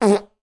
disconnect.wav